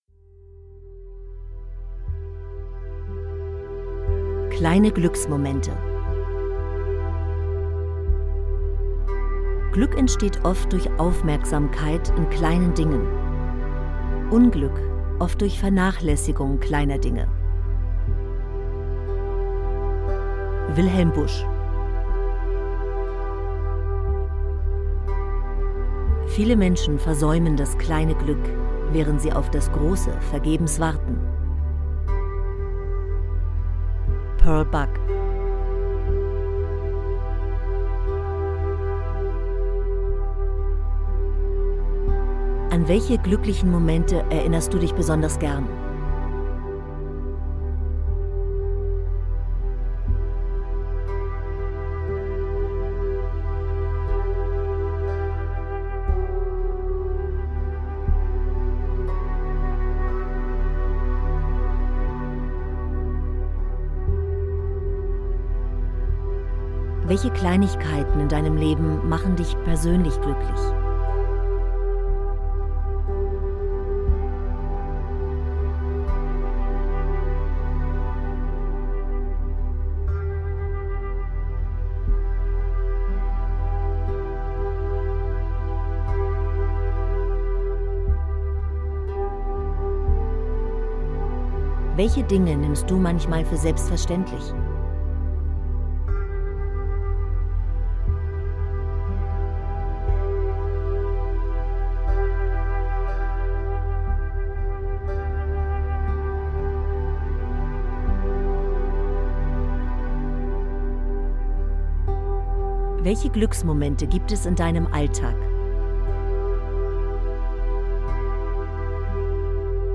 Jede Anwendung ist ein harmonisches Zusammenspiel von inspirierenden Texten, bewegenden Stimmen und sanft stimulierender Begleitmusik.